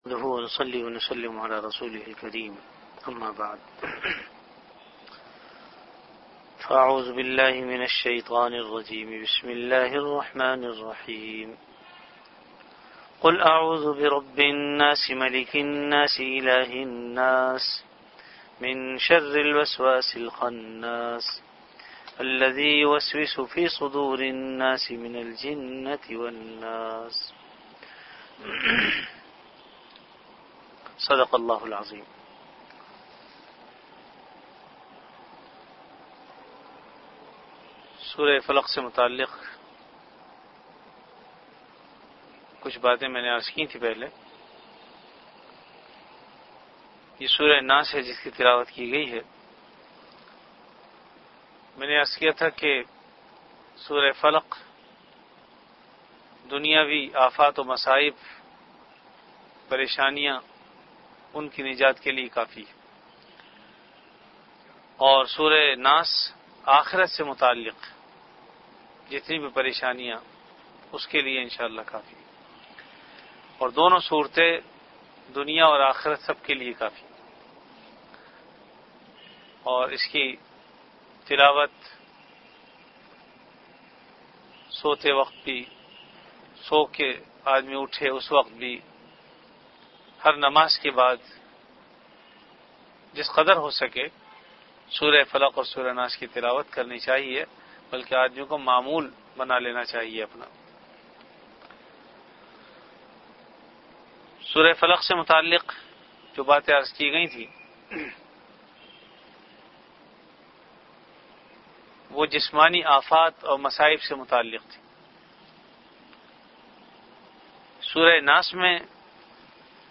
Dars-e-quran · Jamia Masjid Bait-ul-Mukkaram, Karachi
After Isha Prayer